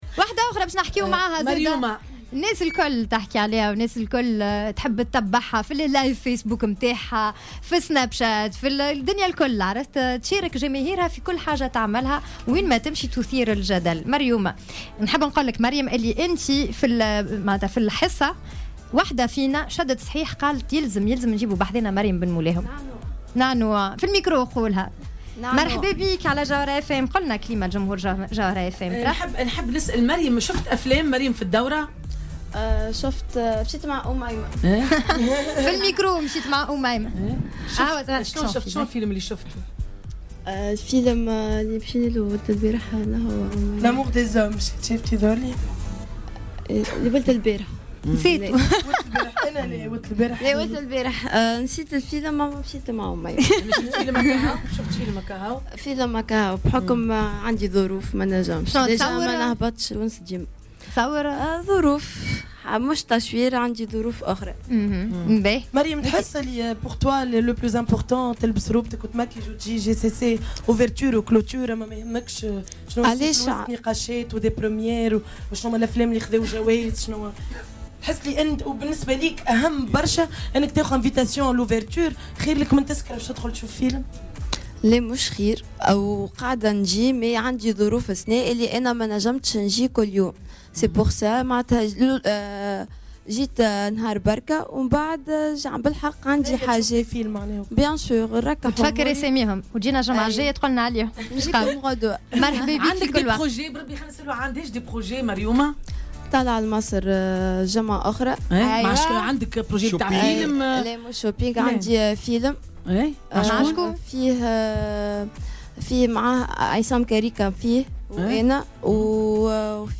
قالت المغنية مريم بن مولاهم، خلال استضافتها في برنامج "دار العيلة" اليوم الأربعاء 08 نوفمبر 2017، إن لا صحة لما يروّج حول طردها خلال افتتاح أيام قرطاج السينمائية من طرف الممثلة منى نور الدين.